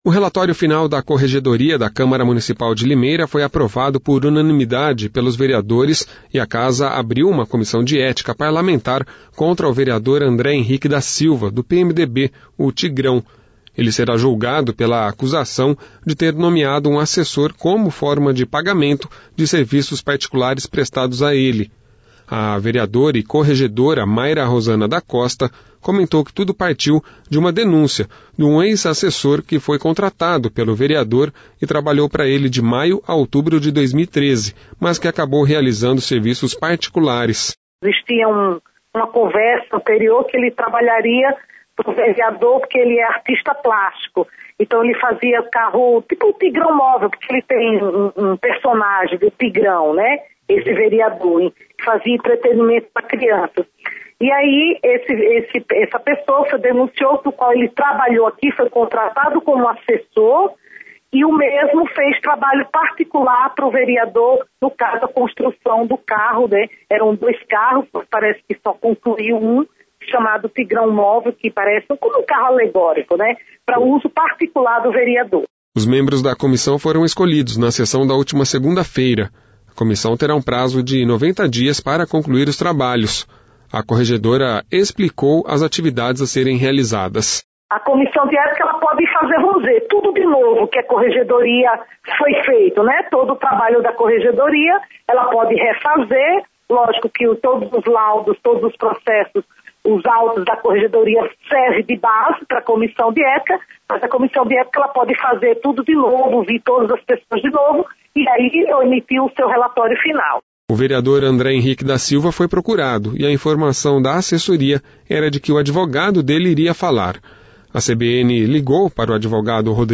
A vereadora e corregedora Mayra Rosanna da Costa comentou que tudo partiu de uma denúncia de um ex-assessor que foi contratado pelo vereador e trabalhou para ele de maio a outubro de 2013 mas que acabou realizando serviços particulares